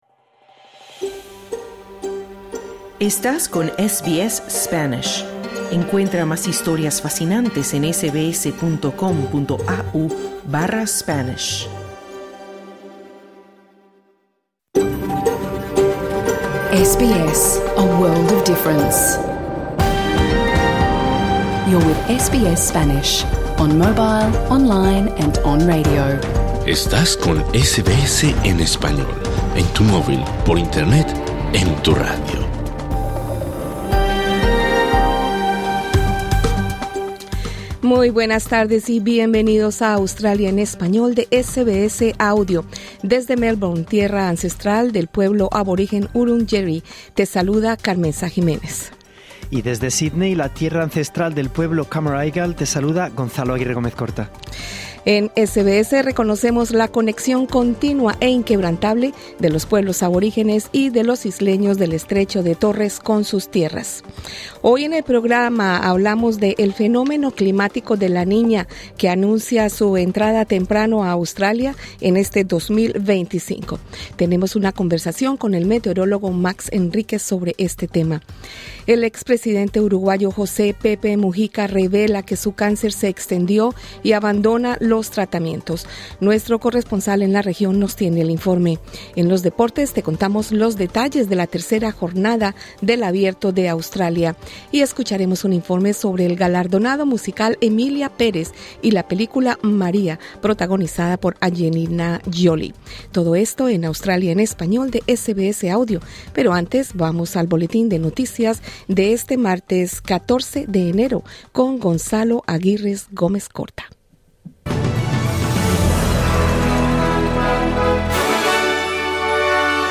Programa en Vivo | SBS Spanish | 14 enero 2025